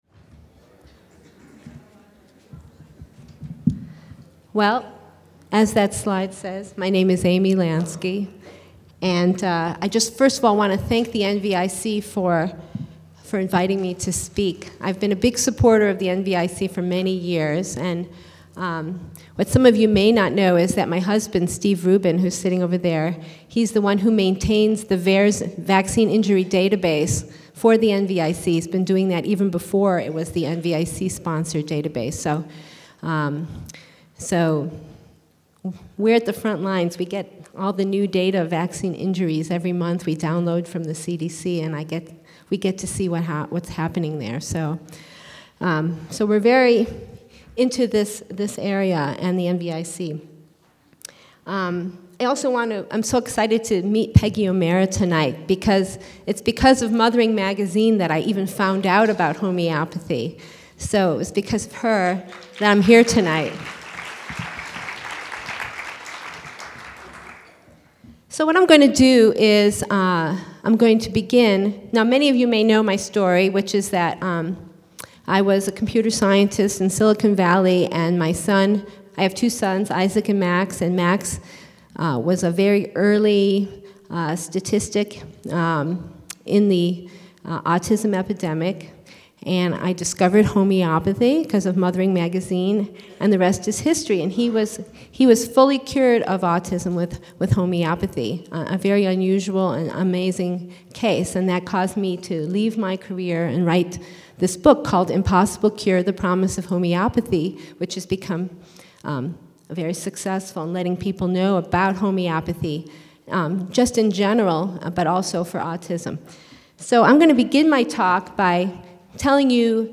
Below is a link to an mp3 of me speaking in 2009 at the National Vaccine Information Center’s 4th International Public Conference on Vaccination.